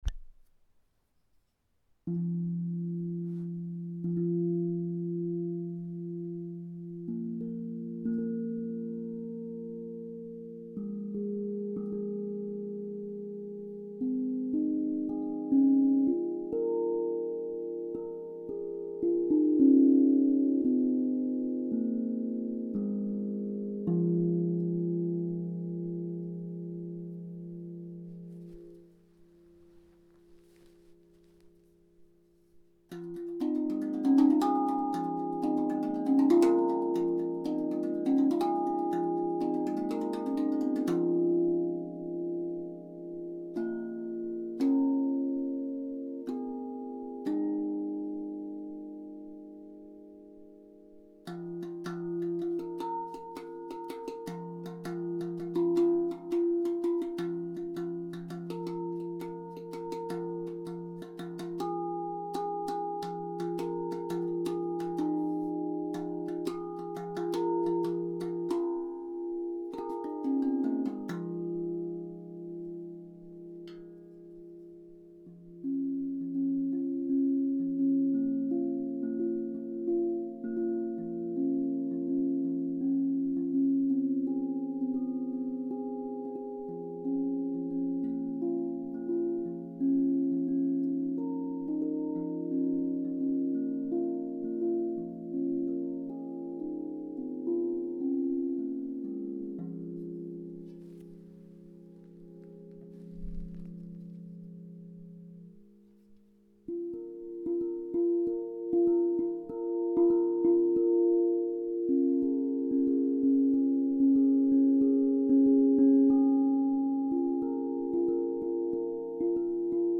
Tank drum Fa octave 432 hz - Osb Drum
L'utilisation de cet instrument vous procurera une expérience douce et vous apportera relaxation. Il est puissant et profond en Fa 432 hz, la basse centrale est associée au chakra du cœur, écoutez le avec un casque de préférence.
Fa-octave-432-hz.mp3